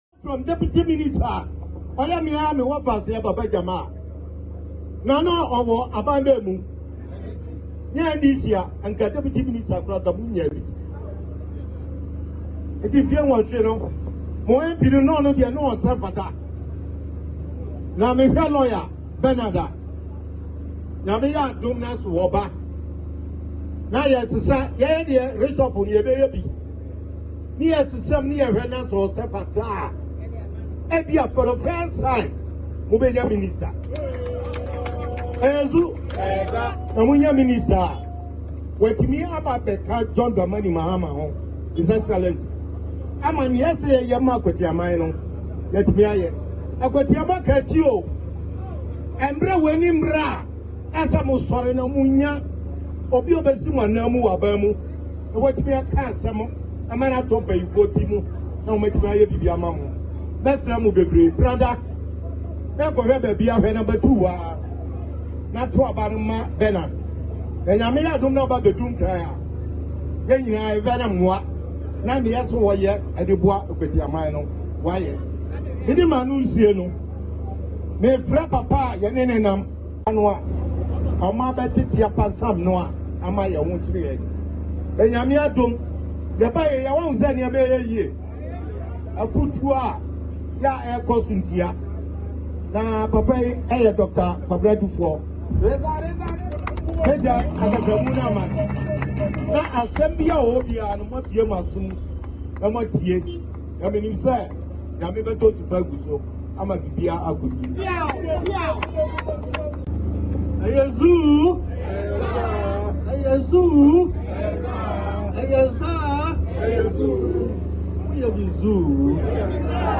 Dr. Kwabena Duffuor addresses Akwatia Constituents ahead of by-election...